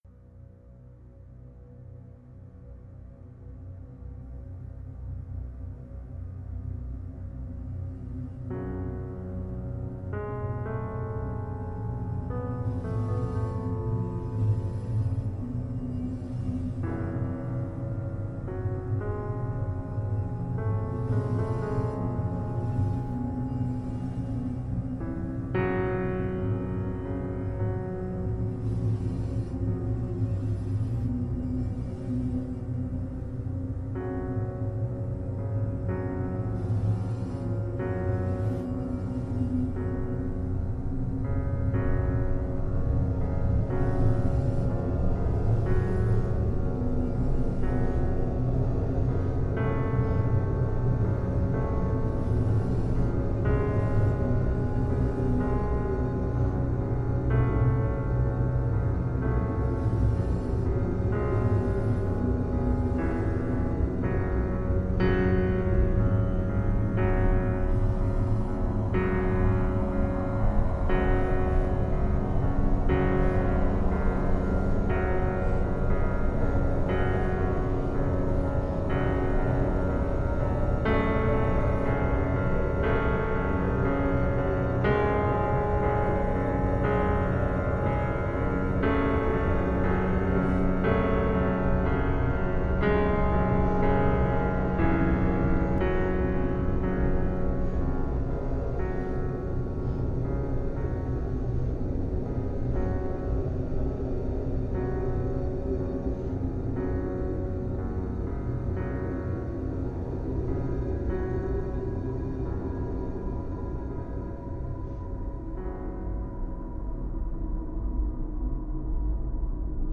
Strumenti: Pianoforte e Synth
instrumental version